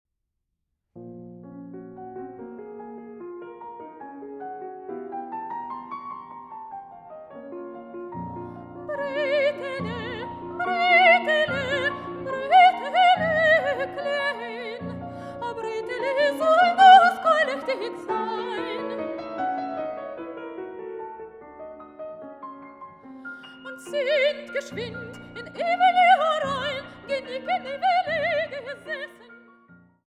Sopranistin
Pianistin